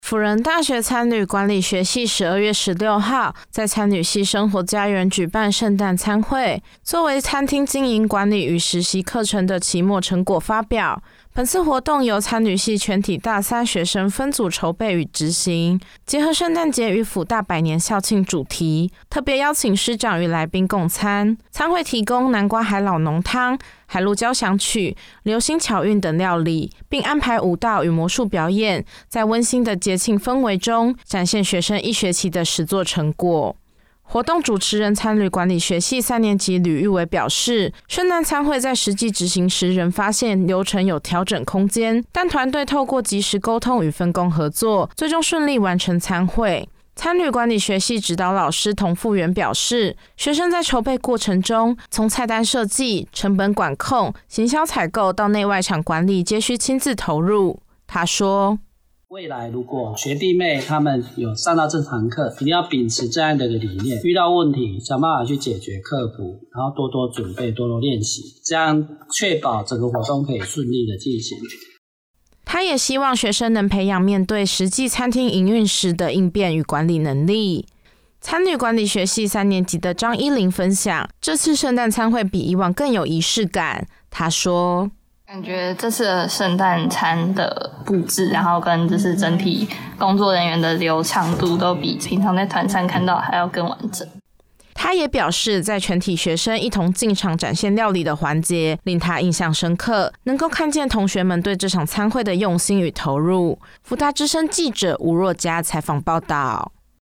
採訪報導